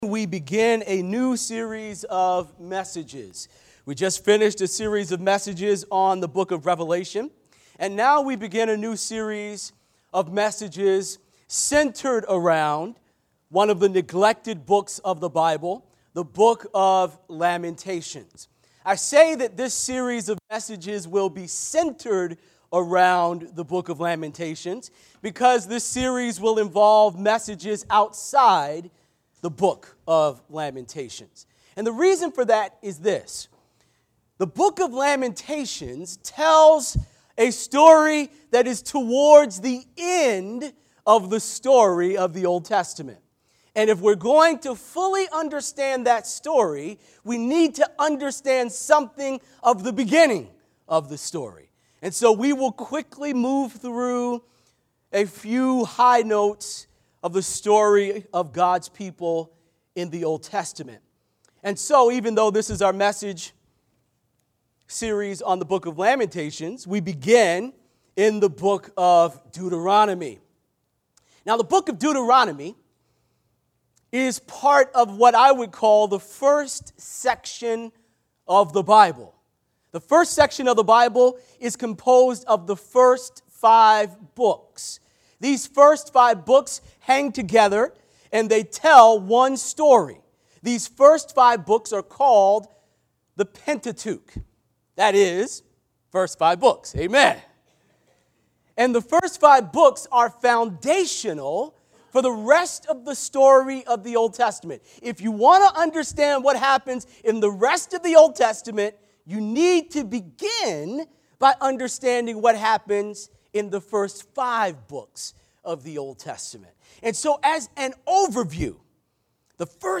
Crossroads Church of Hillside Sermons